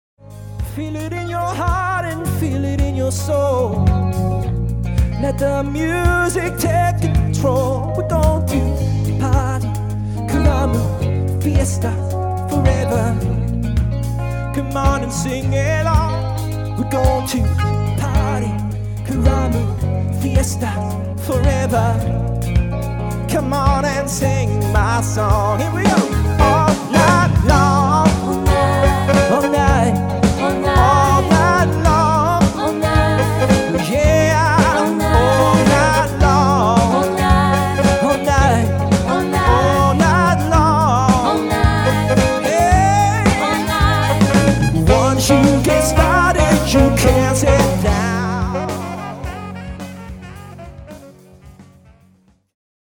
sophisticated and energetic band
Demo’s